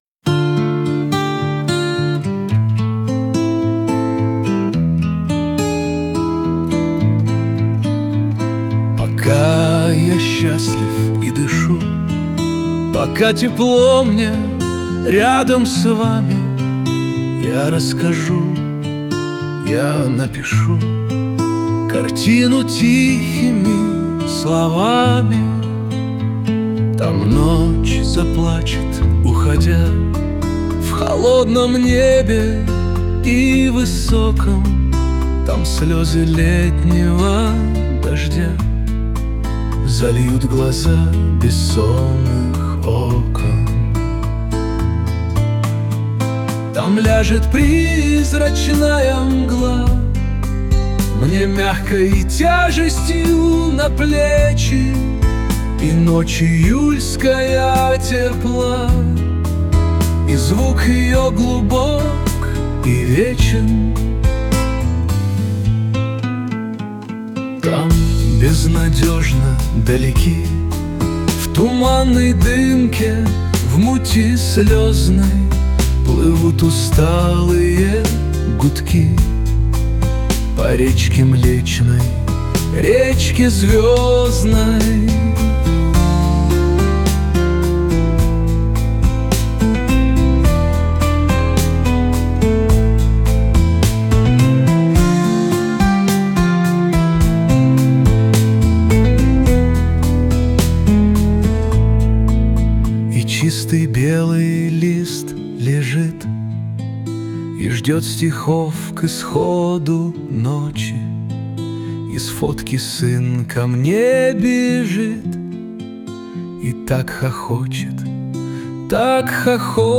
Â ñëîâå ìóòè óäàðåíèå íå òóäà óïàëî. ß ñåé÷àñ ýòî â ðåäàêòîðå ïðàâëþ.
Íî ìíå ïîêàçàëîñü, ÷òî ìóçûêà, êîòîðóþ èçëàäèë ÈÈ, óäèâèòåëüíî ïîäõîäèò ê íàñòðîåíèþ òåêñòà, ïîýòîìó è âûëîæèë.
êîìïîçèöèÿ äîâîëüíî îðãàíè÷íàÿ, ôèíàë, ìÿãêî ãîâîðÿ, íå èç ýòîé îïåðû